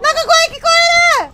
Worms speechbanks
Incoming.wav